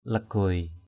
/la-ɡ͡ɣʊoɪ/ (cv.) laguai l{=g& (t.) mệt = fatigué. tired. lagah laguai lgH-l=g& mỏi mệt = épuisé.